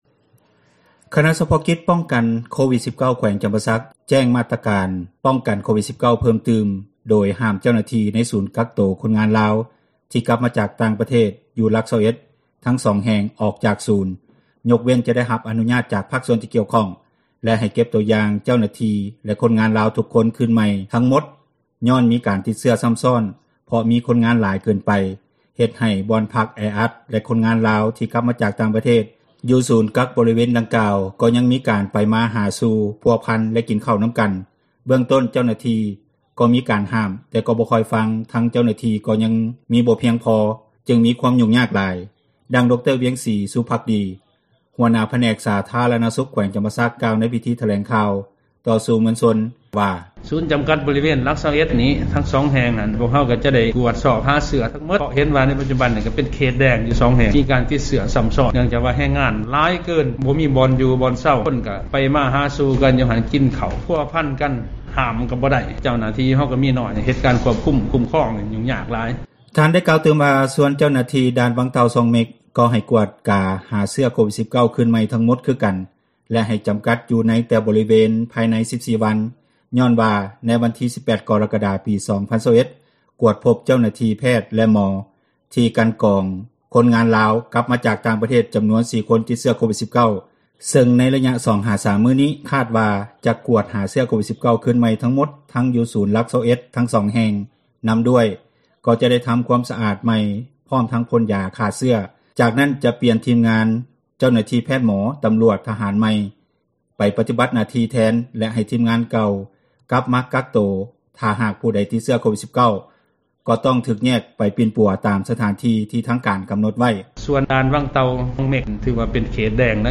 ເບື້ອງຕົ້ນເຈົ້າໜ້າທີ່ ກໍມີການຫ້າມ ແຕ່ກໍບໍ່ຄ່ອຍຟັງ ທັງເຈົ້າໜ້າທີ່ ກໍຍັງບໍ່ພຽງພໍ ຈຶ່ງມີຄວາມຫຍຸ້ງຍາກຫຼາຍ, ດັ່ງ ດຣ.ວຽງສີ ສຸພັກດີ ຫົວໜ້າຜແນກສາທາຣະນະສຸຂ ແຂວງຈຳປາສັກ ກ່າວໃນພິທີຖແລງຂ່າວ ຕໍ່ສື່ມວນຊົນ ໃນວັນທີ 09 ກໍຣະກະດາ ວ່າ.